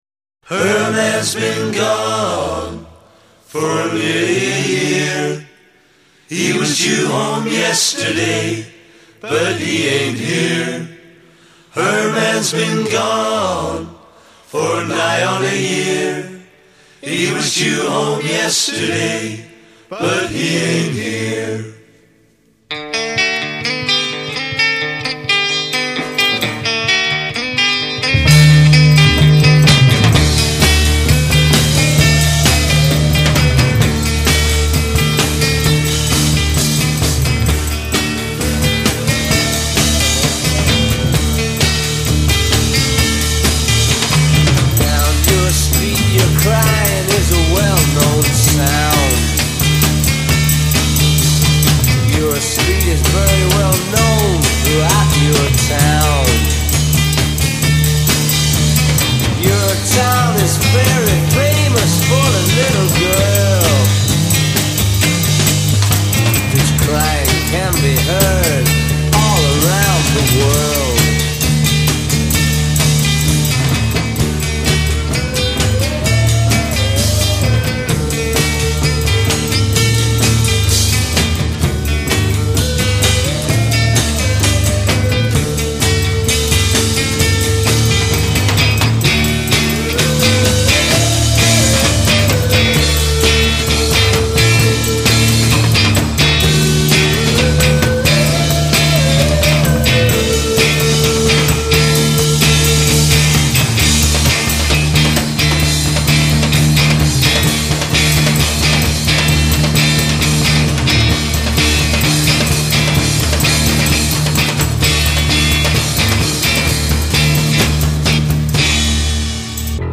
Sung imitation of crying.
Instrumental transition with sung cry; builds in intensity.